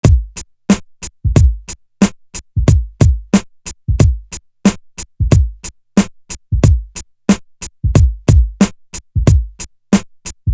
Big Twins Drum.wav